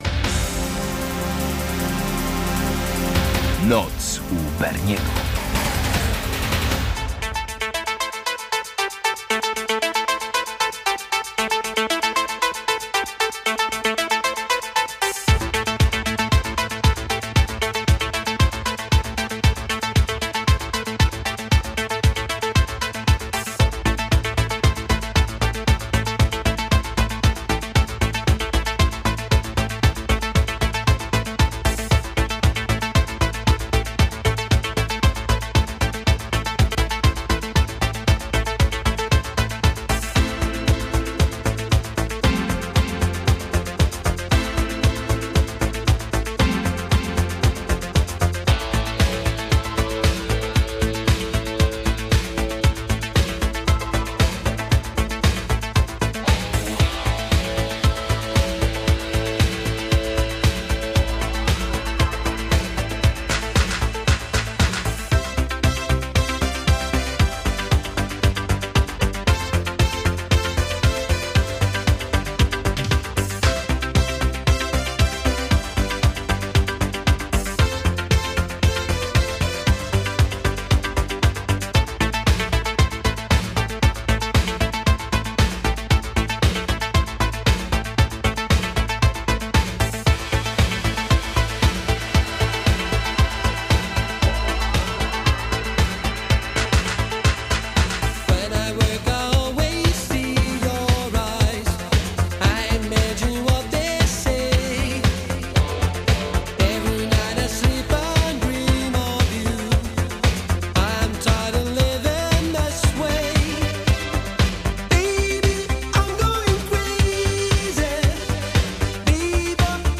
Dominujący gatunek: italo i euro-disco